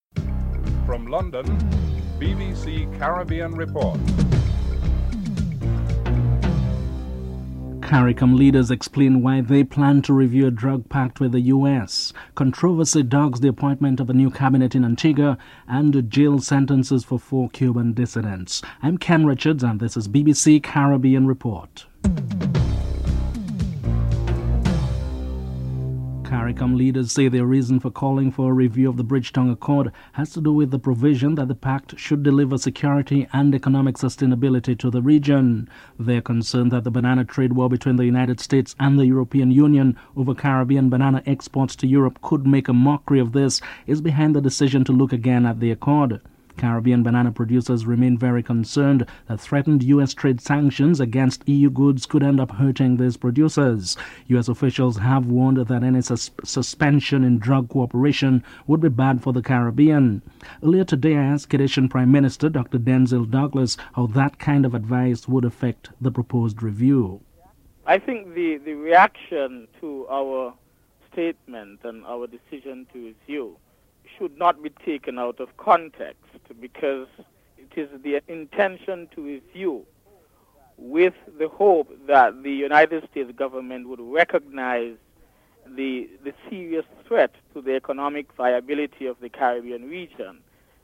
1. Headlines with anchor